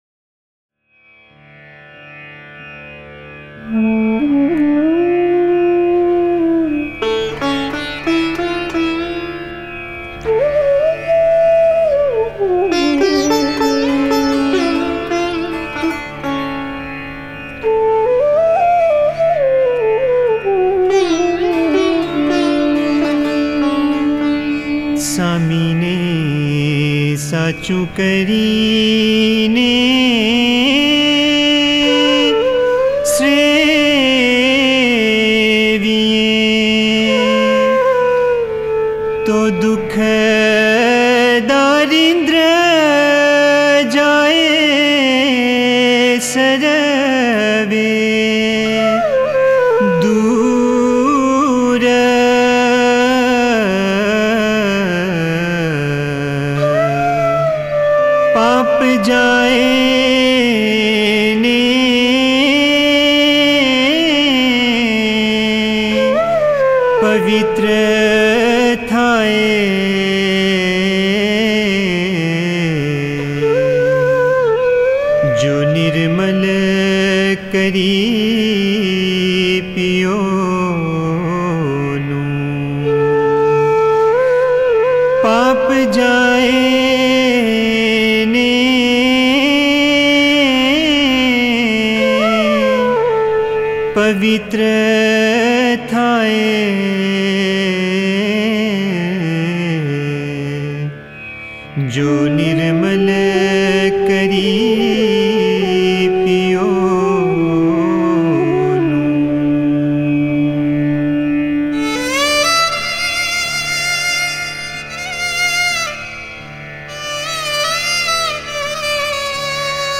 Ginan: Uncha Re – Soul’s yearning for the Vision/Deedar
The venti ginan Uncha Re Kot Bahuvech is set in Raag Sindhoora, traditionally sung during monsoon, this raag has an overall romantic feel.